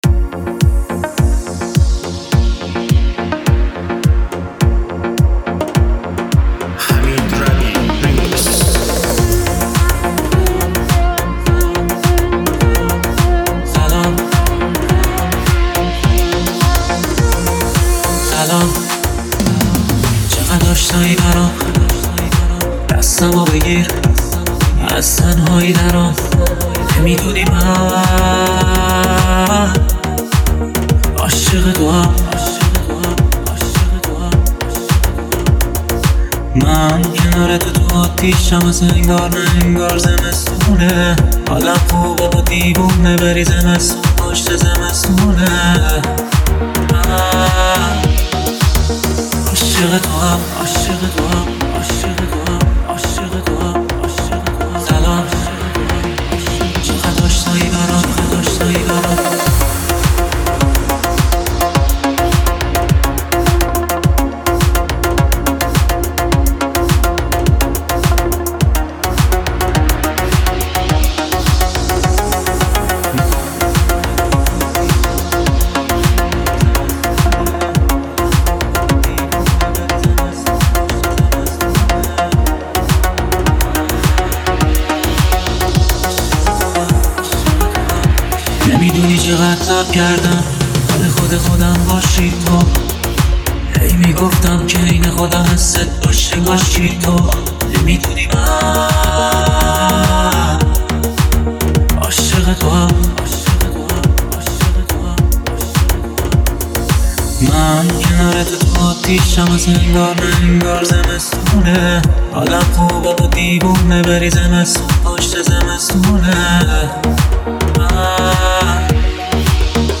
ریمیکس قطعه